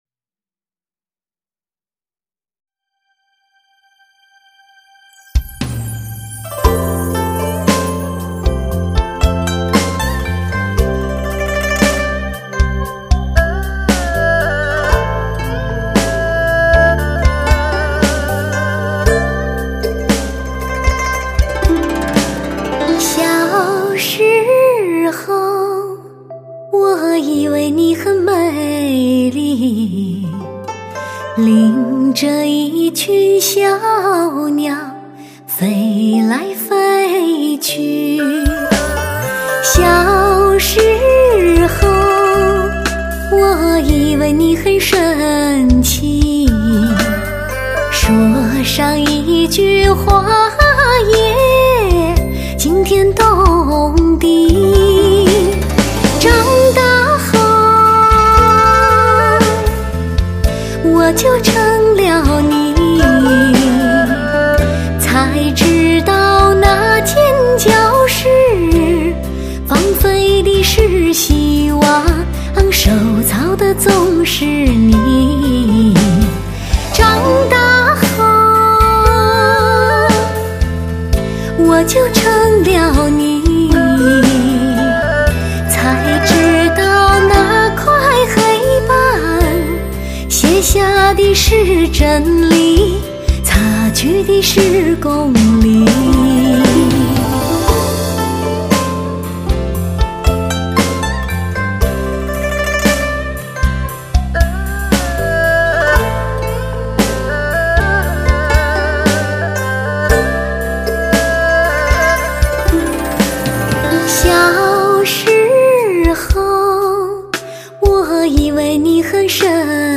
无与伦比的音质声线 无可挑剔的绝美靓声
类型: HIFI试音